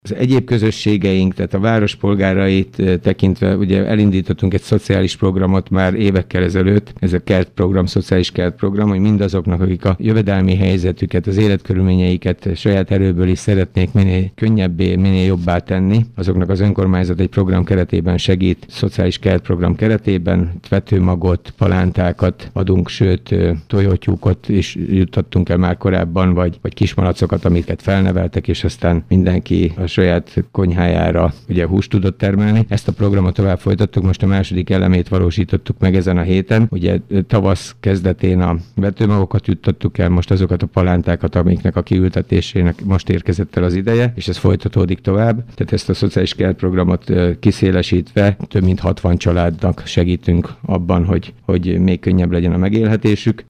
Kőszegi Zoltán polgármester elmondta, az idei vetőmagos akció után a héten palántákat osztottak mintegy 60 családnak.